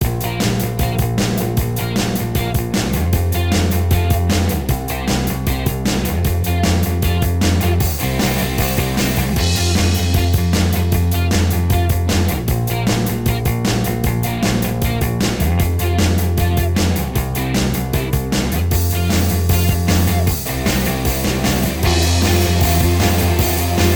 Minus Lead Guitar Rock 3:52 Buy £1.50